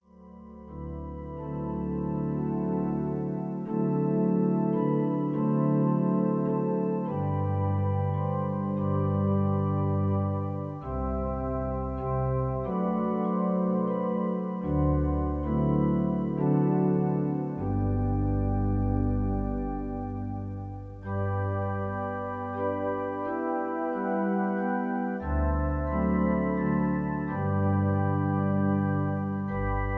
Der Klassiker unter den Kirchenliedern